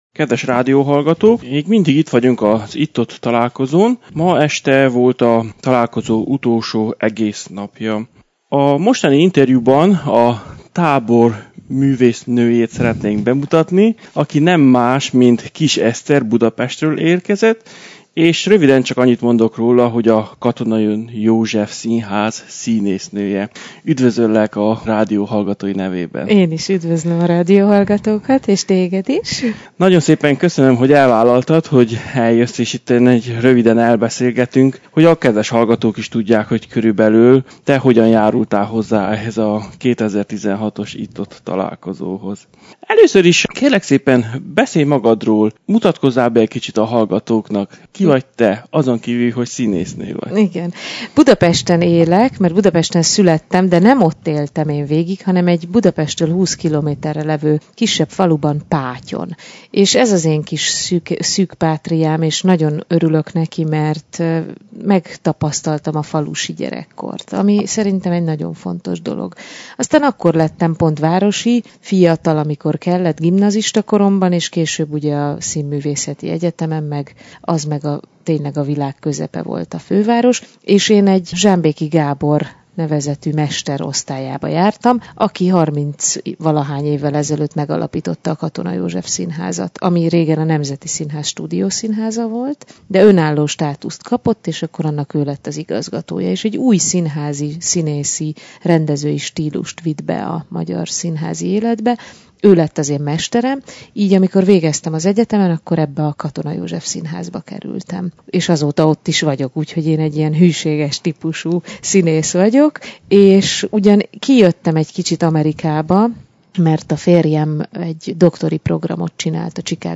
A teljes interjút az alábbi linken lehet meghallgatni. https